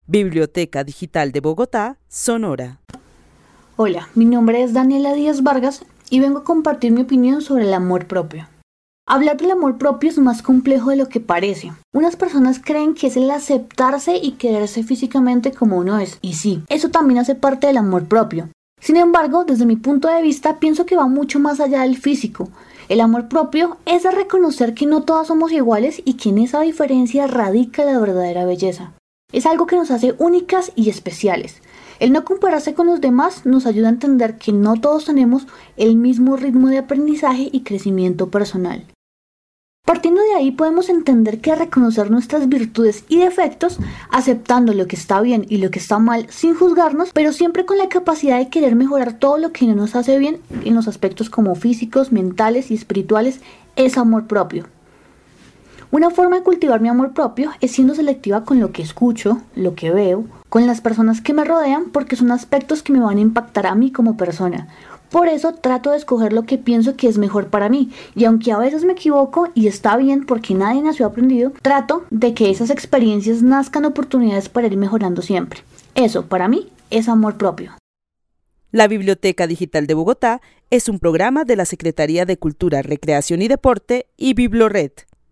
Narrativas sonoras de mujeres